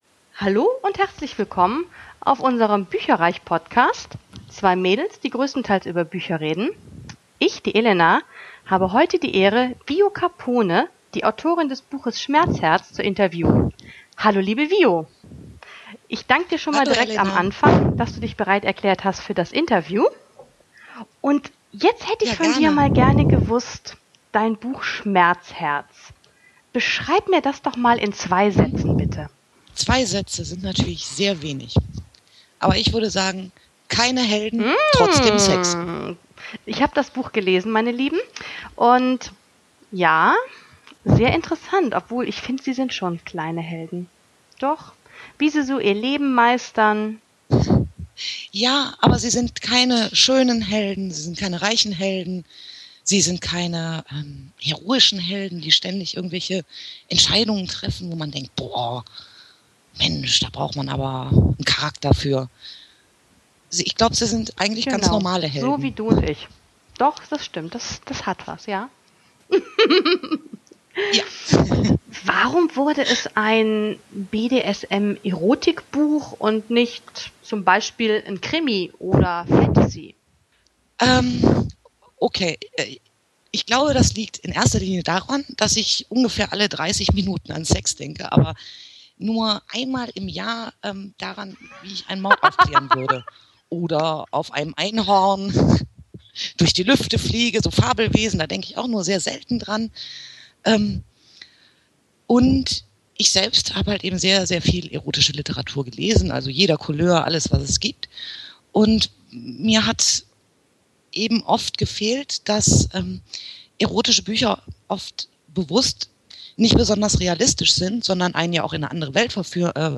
bücherreich Sonderausgabe 023 - Interview